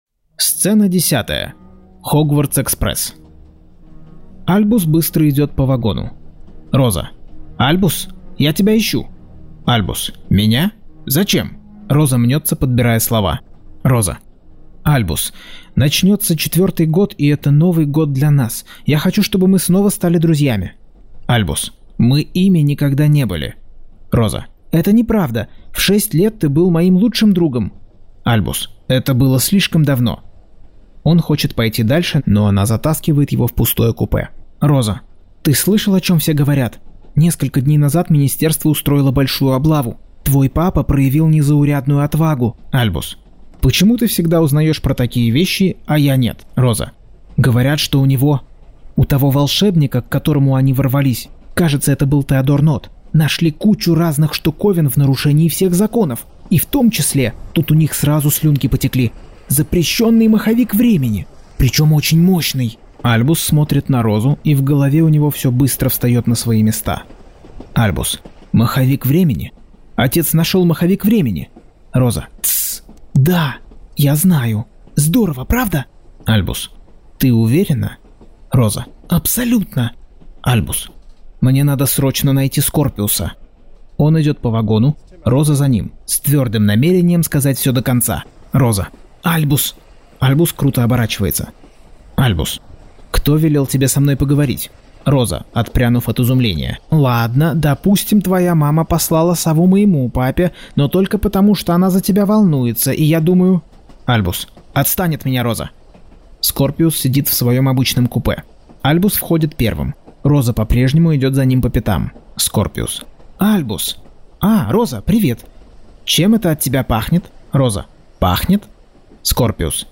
Аудиокнига Гарри Поттер и проклятое дитя. Часть 7.